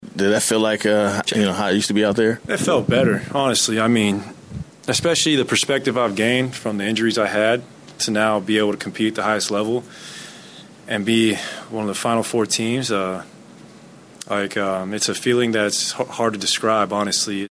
Following the game, Klay Thompson discusses being back on the big stage: